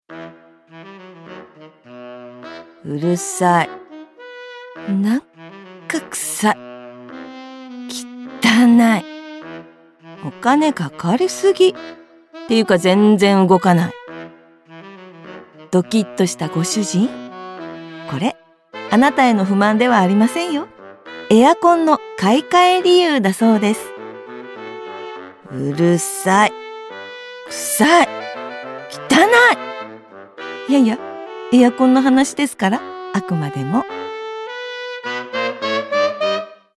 温かく優しい等身大の声で、CMや番組のナレーションを数多く担当。